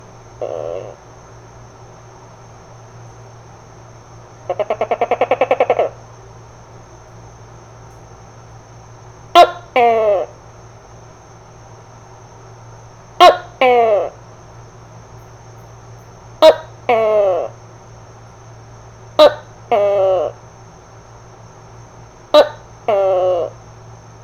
This .wav file is from a Tokay Gecko at my house.
Tokay_1-2.wav